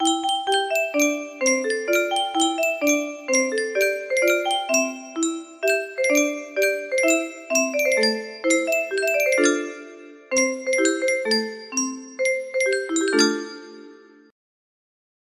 Yunsheng Music Box - Unknown Tune Y522 music box melody
Full range 60